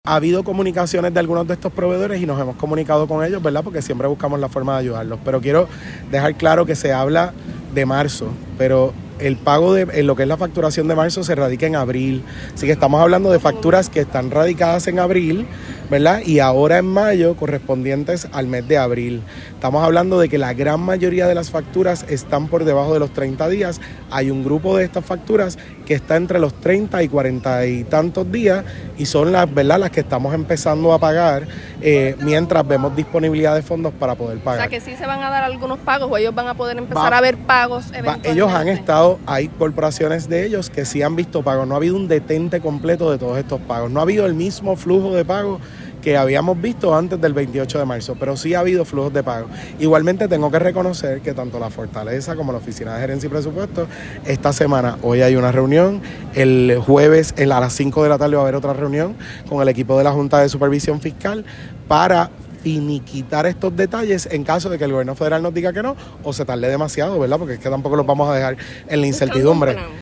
Las expresiones del secretario se dieron durante la congregación de bandas escolares de toda la isla, las cuales fueron desfilando por toda la avenida Carlos Chardón, en ruta hacia el DE en plena semana educativa.